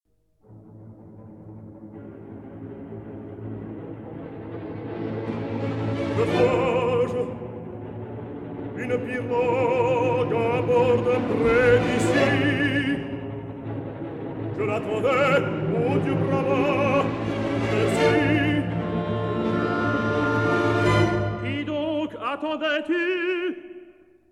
soprano
tenor
baritone
bass